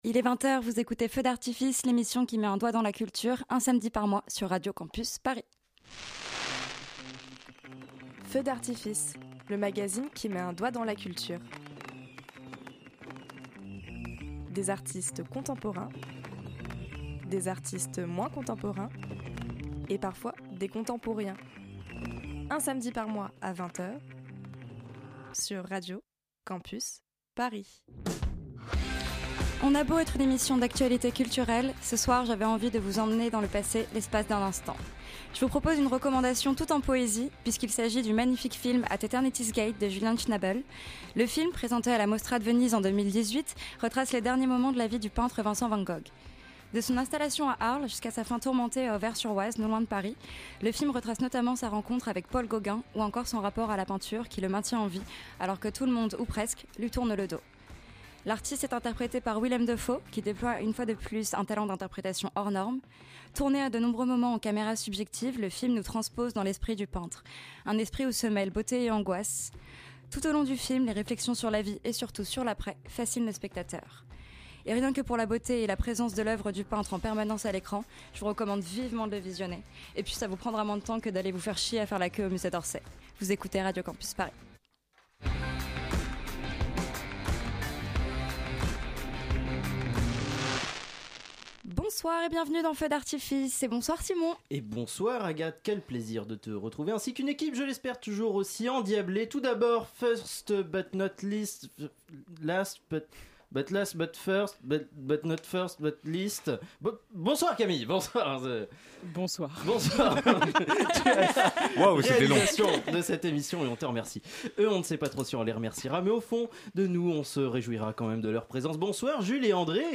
Feu d’ARTifices #2 Émission du 6 novembre 2021 Ce samedi, c’était le retour de Feu d’ARTifices, la nouvelle émission d’entretiens autour de l’actualité culturelle de Radio Campus Paris ! Pour ce deuxième numéro, nous avons reçu Michel Leclerc , réalisateur et scénariste du Nom des gens, Télé Gaucho ou encore de La lutte des classes . Il est venu nous parler de son premier documentaire : Pingouin et Goéland et leurs 500 petits , en salle depuis mercredi !